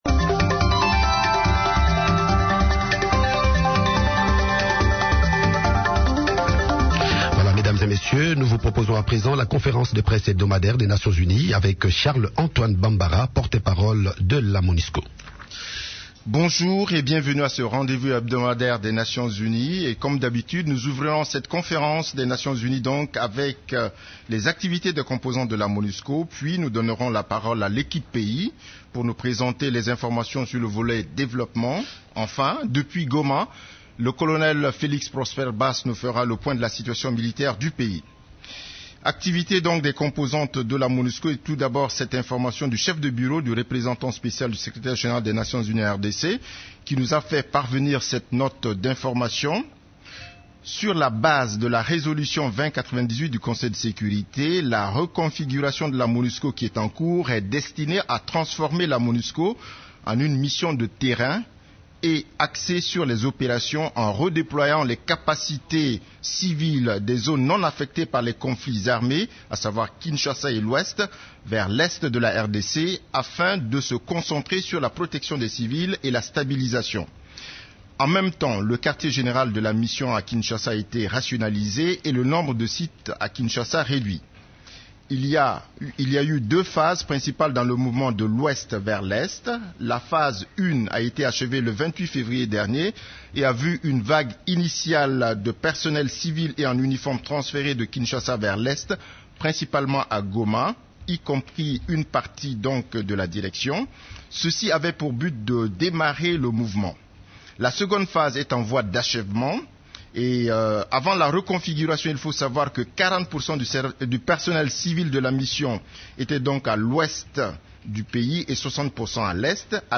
Conférence de presse du mercredi 6 août 2014
La conférence hebdomadaire des Nations unies du mercredi 6 août à Kinshasa a abordé les sujets suivants: